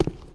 stone05.ogg